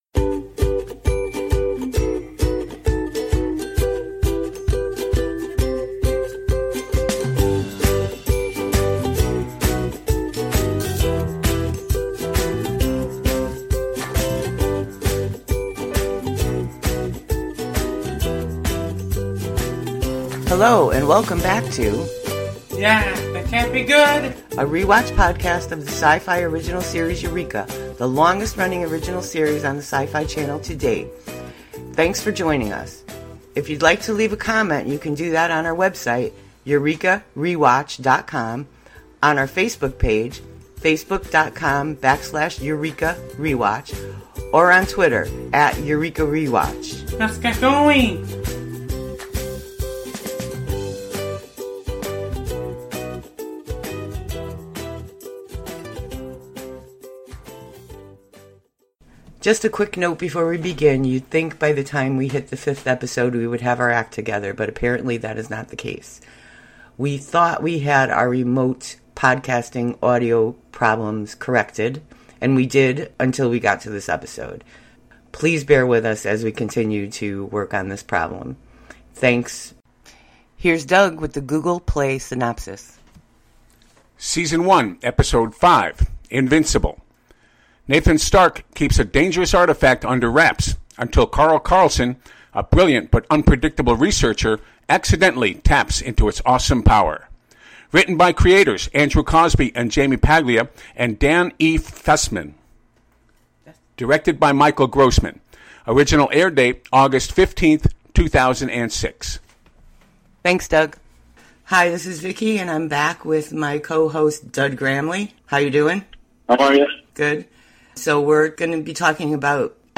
Please note: We had some audio difficulties that we tried to correct as best we could.